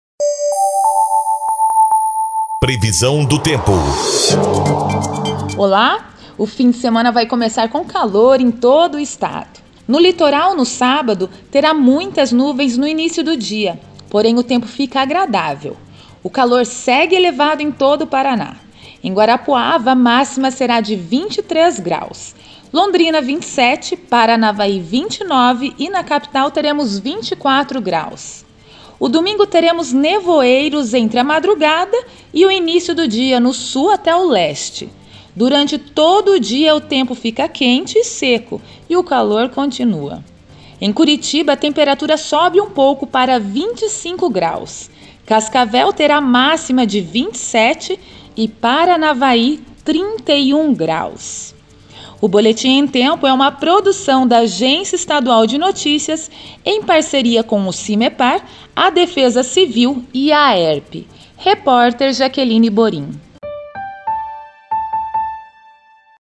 Previsão do Tempo (05 e 06/08)